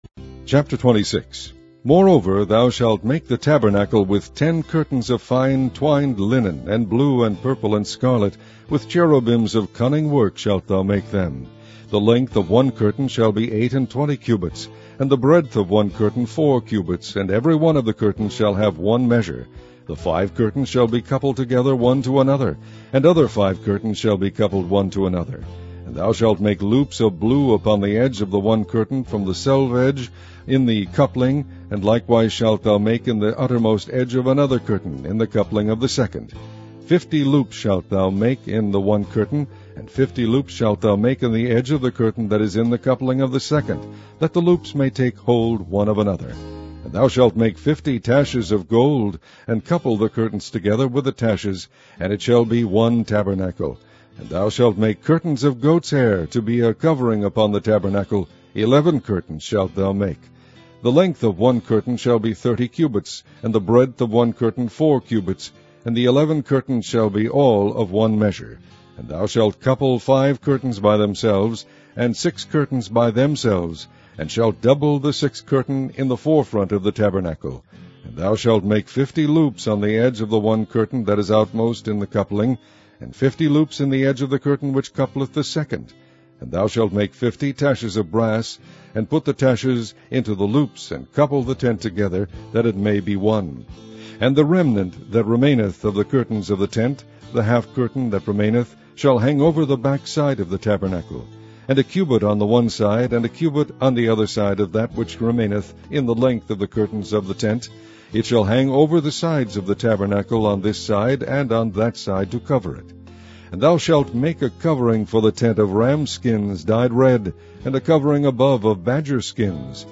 Online Audio Bible - King James Version - Exodus